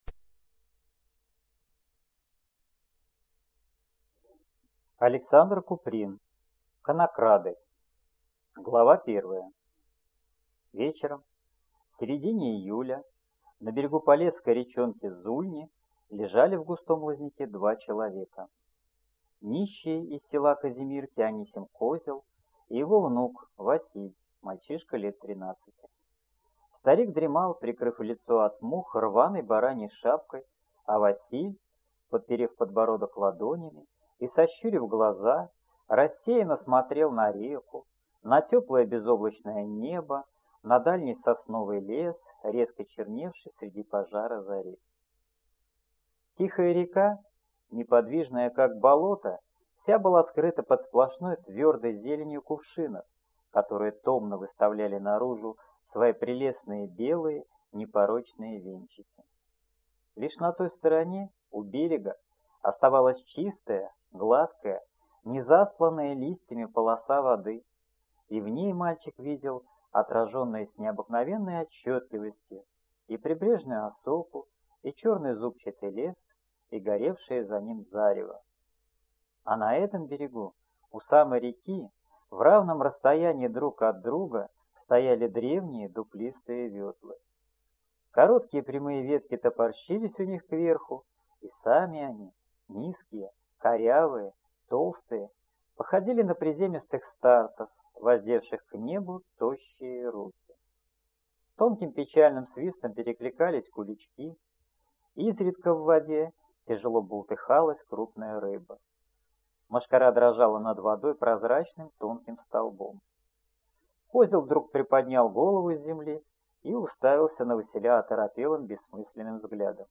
Аудиокнига Конокрады | Библиотека аудиокниг